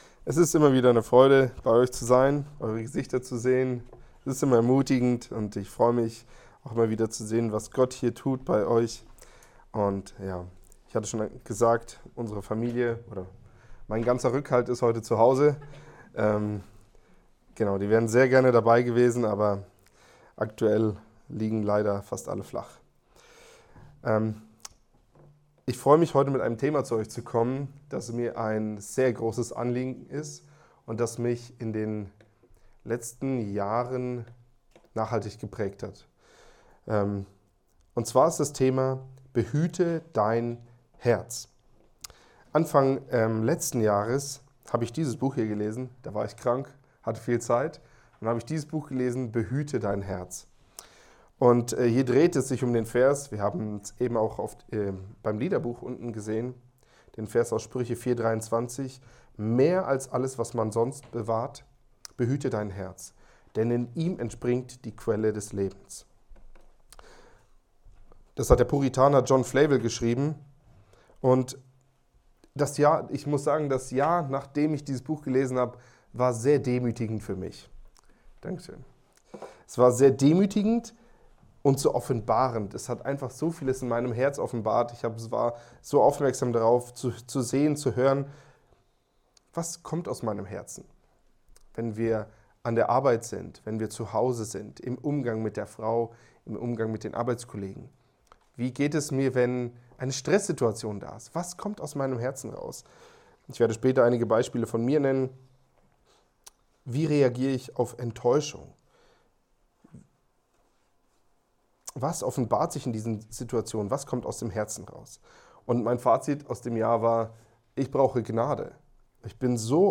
Gottesdienst am 02.02.2020
Predigten